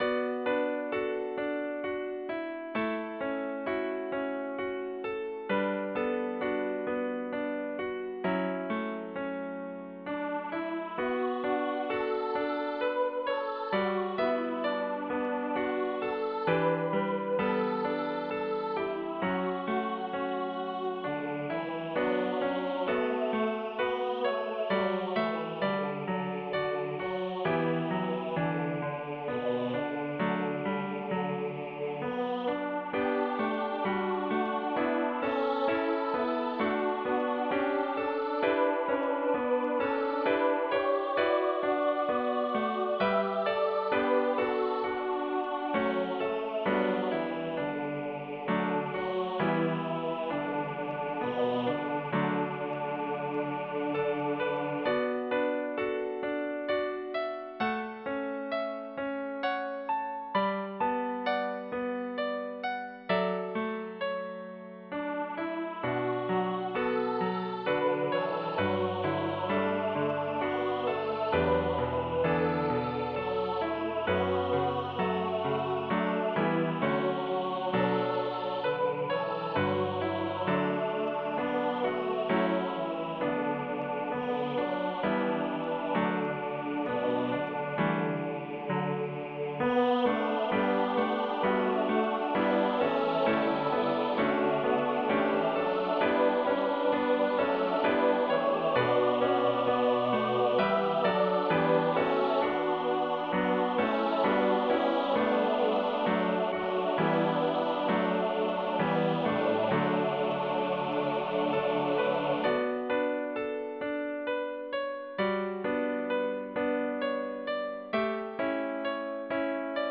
Notez le canon du deuxième couplet.